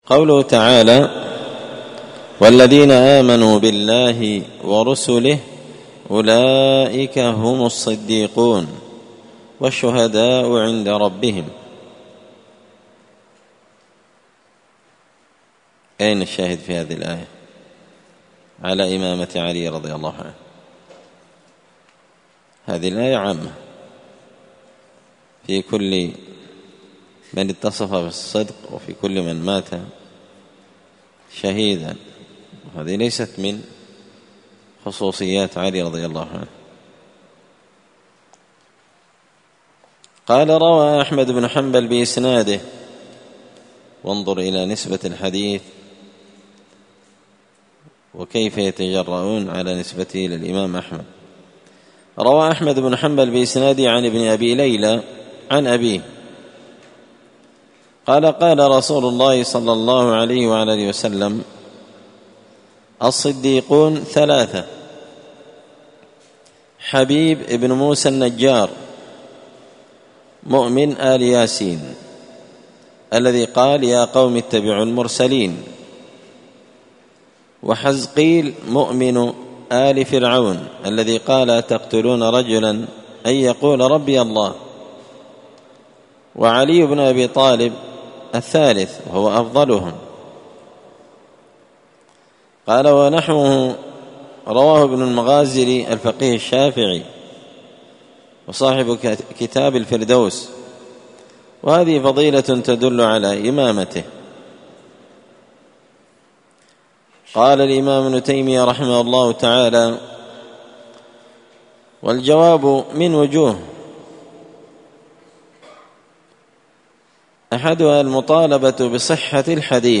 الأربعاء 14 صفر 1445 هــــ | الدروس، دروس الردود، مختصر منهاج السنة النبوية لشيخ الإسلام ابن تيمية | شارك بتعليقك | 8 المشاهدات
مسجد الفرقان قشن_المهرة_اليمن